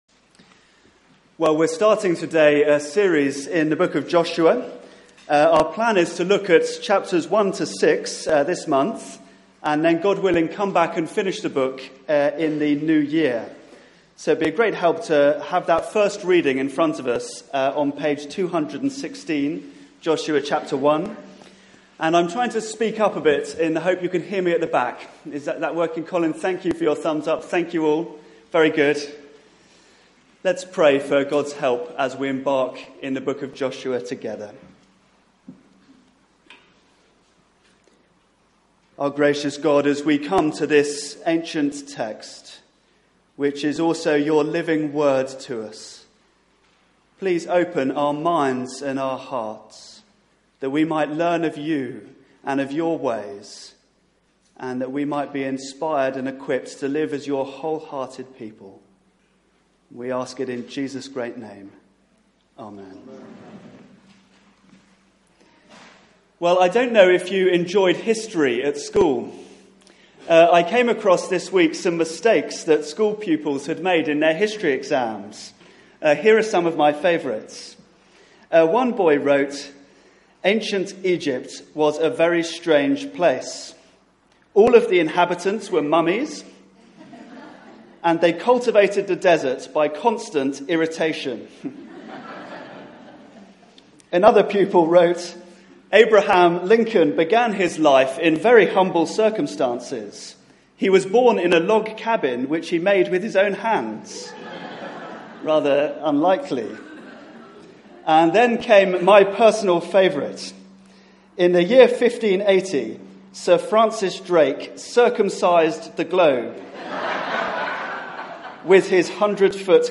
Series: Great Is Thy Faithfulness Theme: The Promise of God Sermon (11:00 Service)